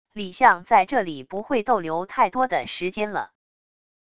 李象在这里不会逗留太多的时间了wav音频生成系统WAV Audio Player